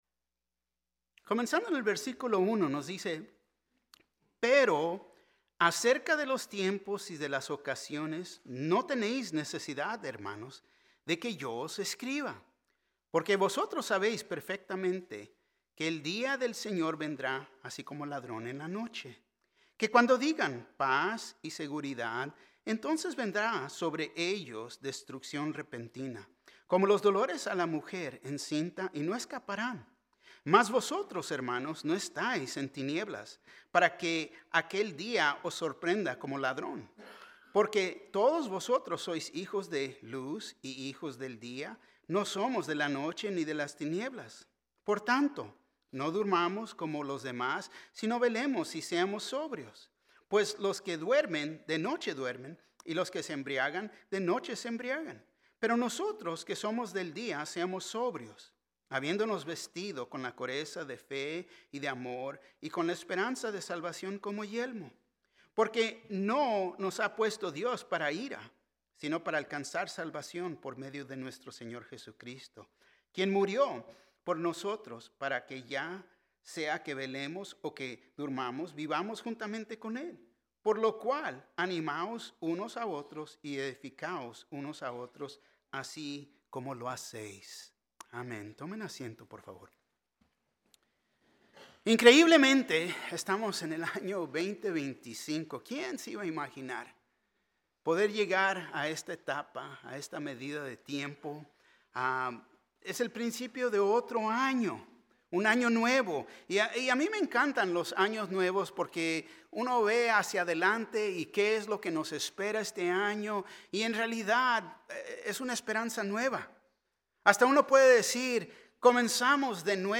Un mensaje de la serie "Estudios Tématicos."